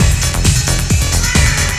TECHNO125BPM 6.wav